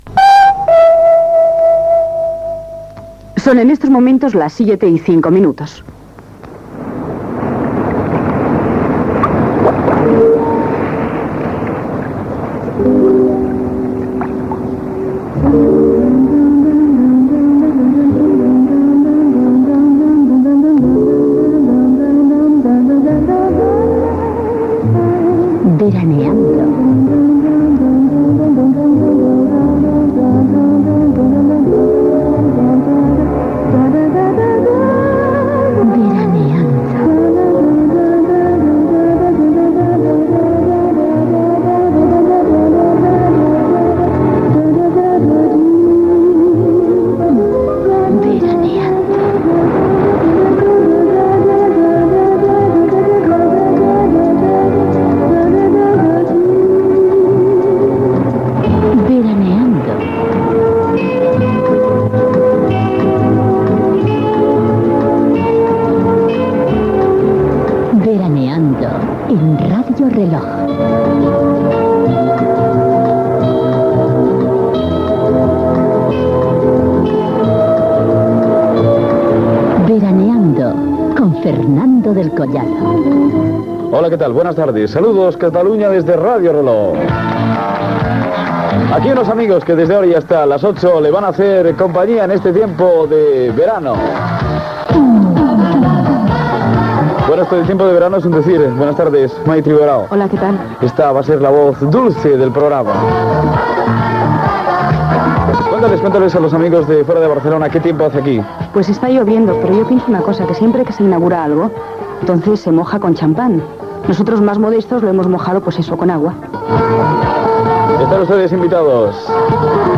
Hora, sintonia del programa, presentació inicial, estat del temps i equip.
Tema musical.
Entreteniment